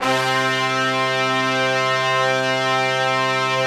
C3 POP BRA.wav